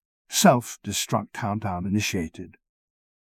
jankboard/client/public/static/voices/en-UK/self-destruct-countdown.wav at main
self-destruct-countdown.wav